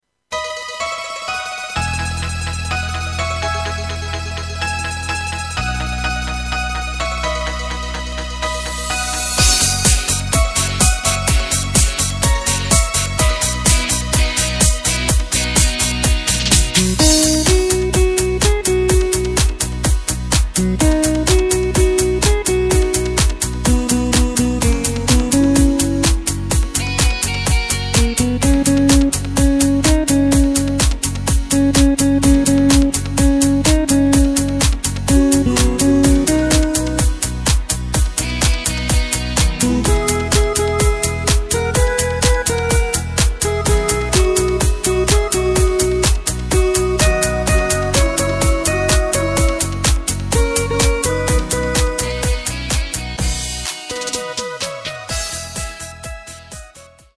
Takt:          4/4
Tempo:         126.00
Tonart:            B
Discofox aus dem Jahr 2013!